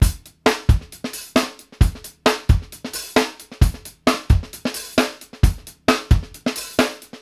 BASIC FUNK-L.wav